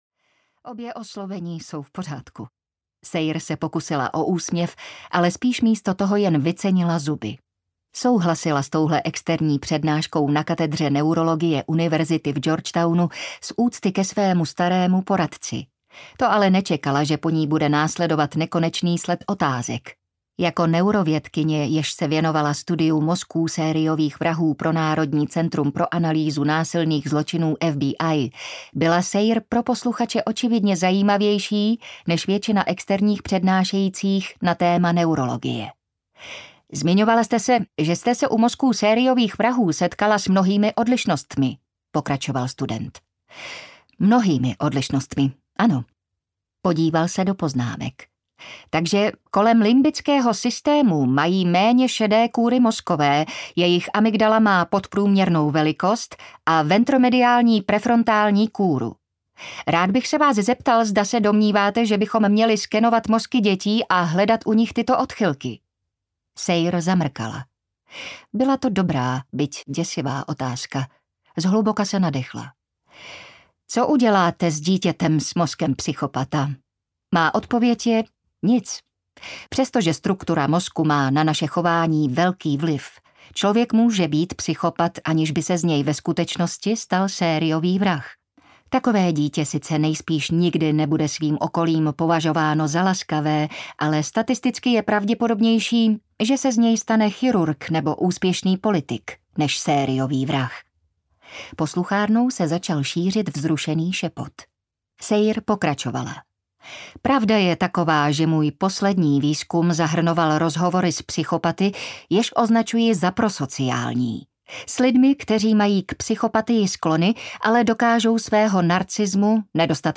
Až na kost audiokniha
Ukázka z knihy